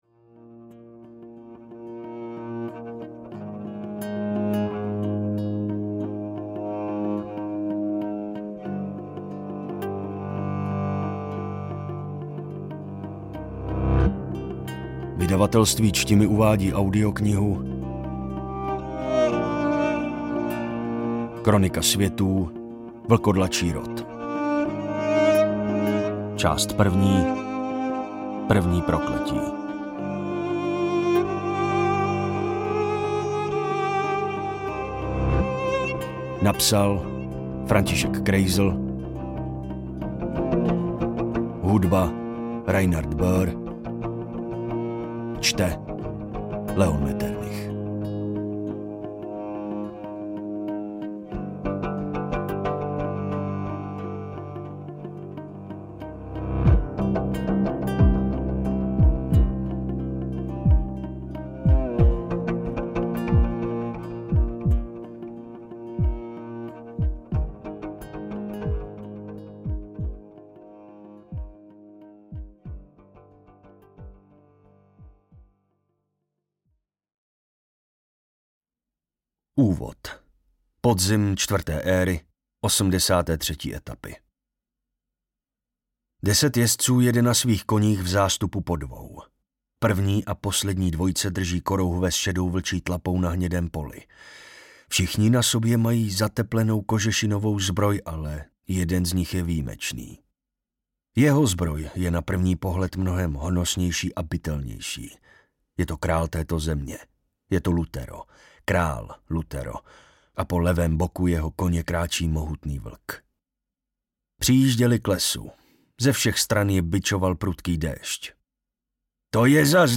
První prokletí audiokniha
Ukázka z knihy